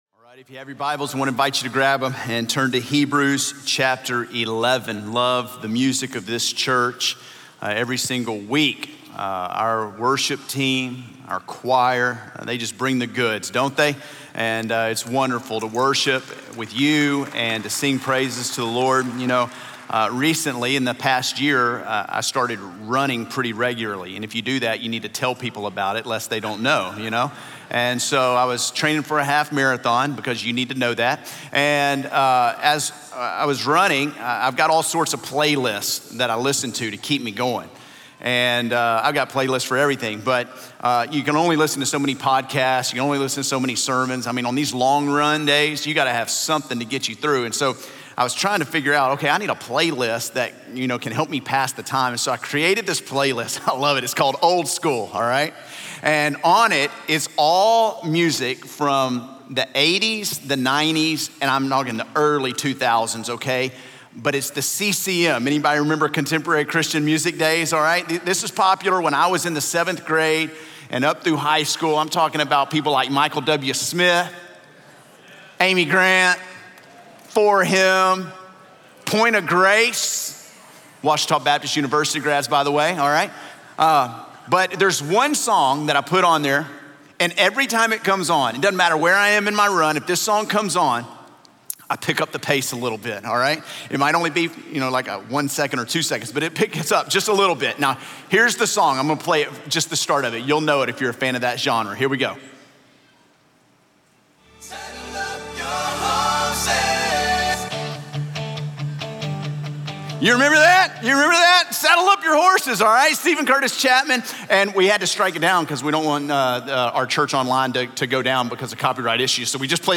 A message from the series "More - NK."